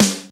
HR16B SNR 01.wav